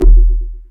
heartbeat rplace.wav